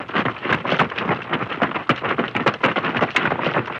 Footsteps on rocks.